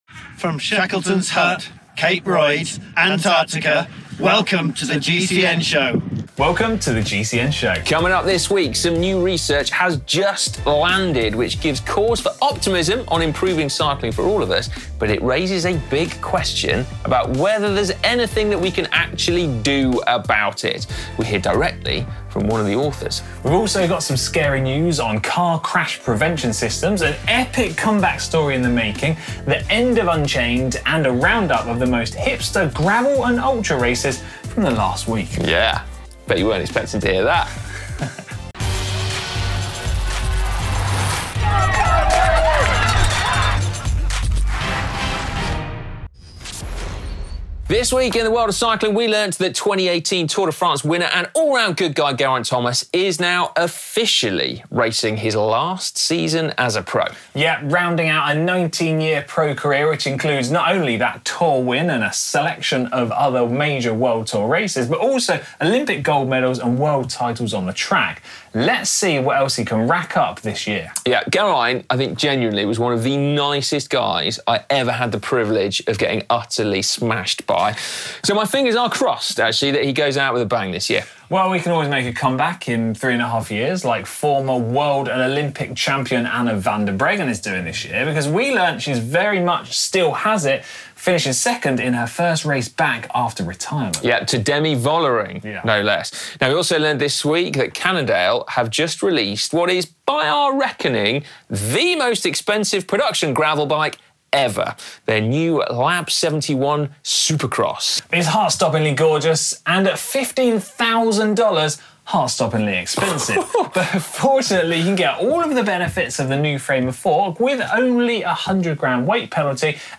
New research just dropped that might give us some optimism on improving cycling for everyone...but can we actually do anything about it? We hear from one of the authors, who explains how the "loud majority" in local activism doesn’t always reflect what most people want.